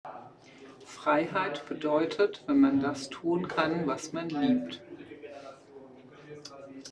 Standort der Erzählbox:
MS Wissenschaft @ Diverse Häfen
no name hat auf die Frage: Was heißt für Dich Freiheit? geantwortet. Standort war das Wechselnde Häfen in Deutschland.